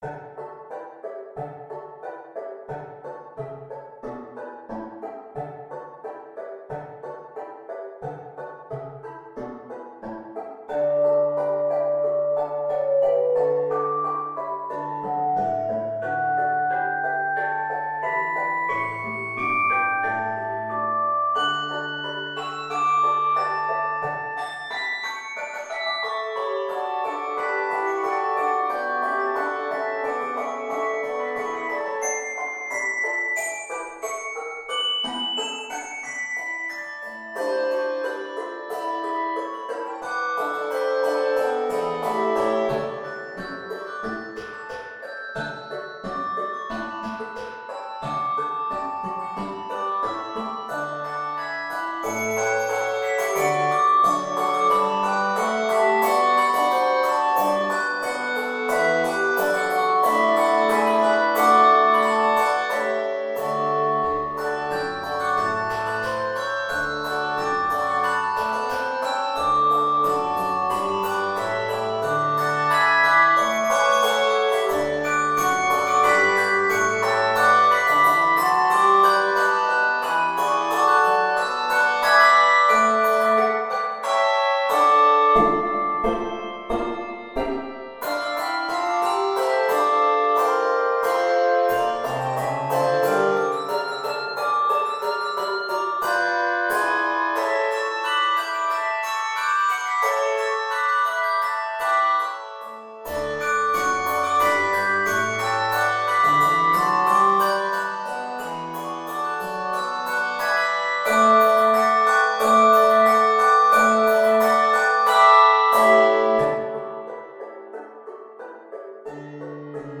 Key of D major.